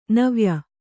♪ navya